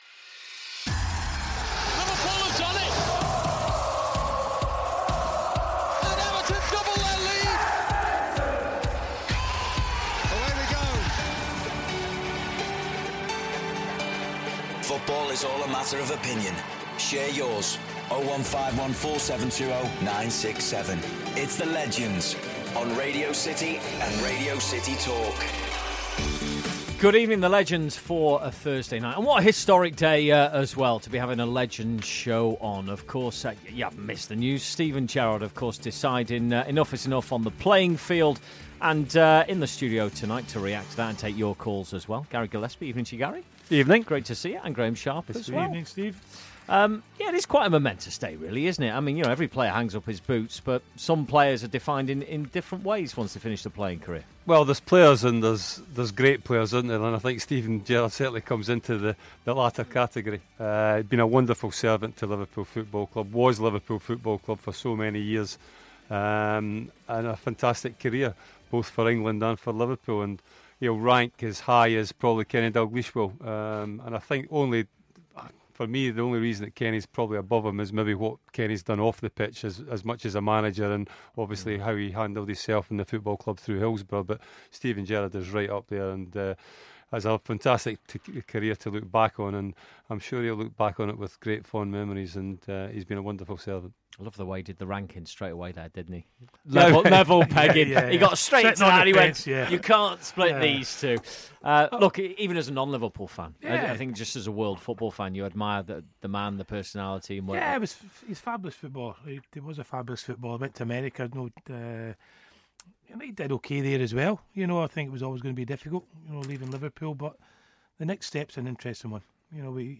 This week the lads discuss the news of Steven Gerrard's retirment and another great in Sami Hyypia stops by for a chat.